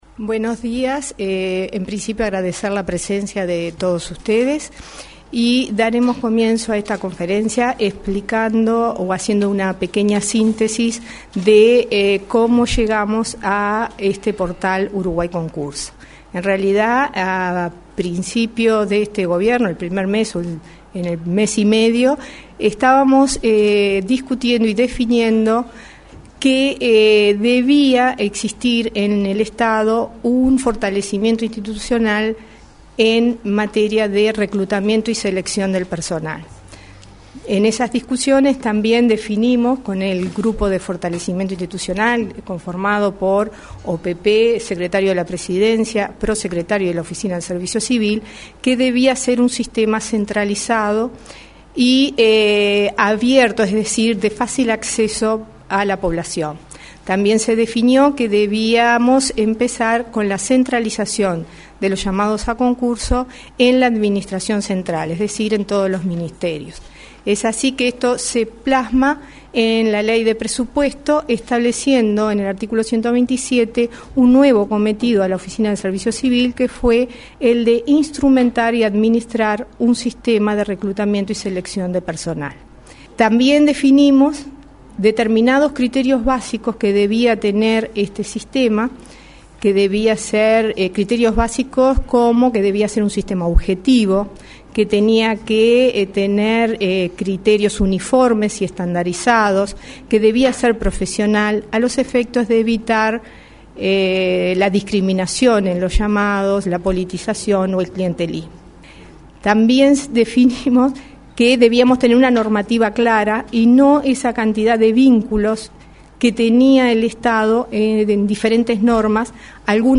Archivo de transmisiones en vivo - marzo 2011
Palabras de la Directora de ONSC,  Elena Tejera, en el lanzamiento del Portal "Uruguay Concursa"